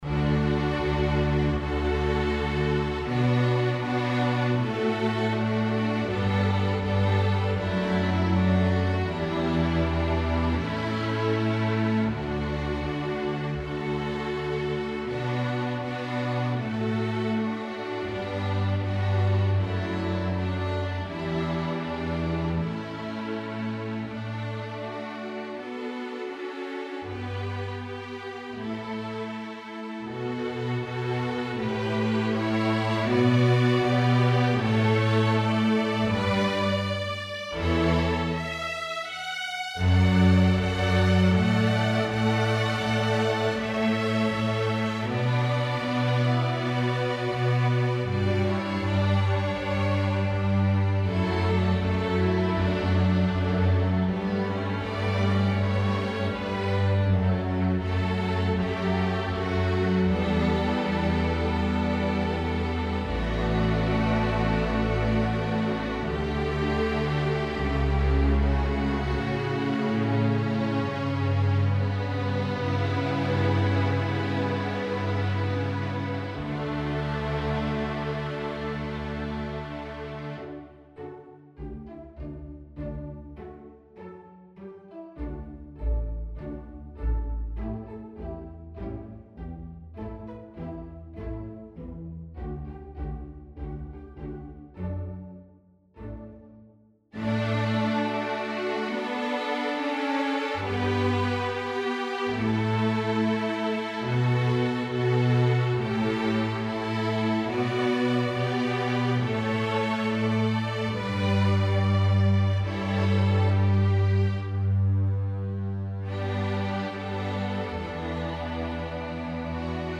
Genre: String Orchestra
Violin I
Violin II
Viola
Cello
Double Bass